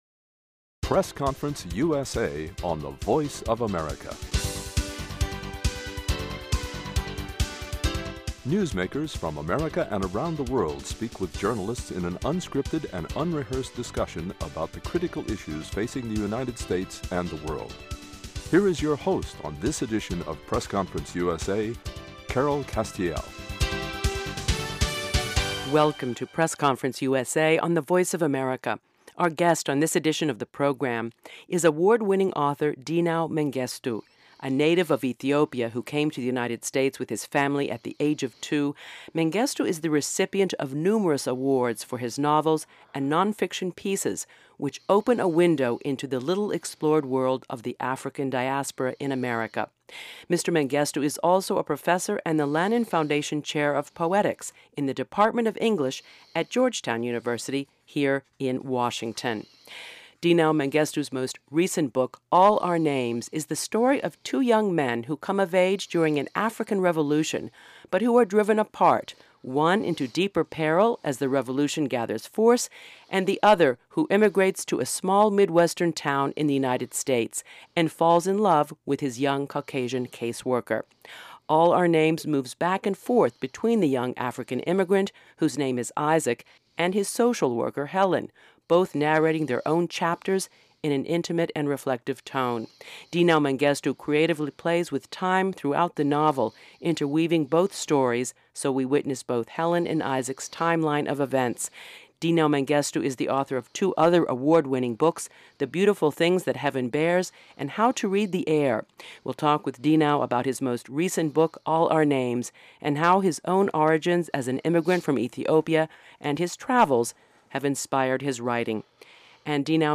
talks with award-winning author Dinaw Mengestu, about his most recent novel “All Our Names.” The Ethiopian born Mengestu discusses the characters and themes in this political novel, which creatively explores universal questions of identity, love, race, and revolution against the backdrop of post-colonial Africa and the post-civil rights movement in the United States.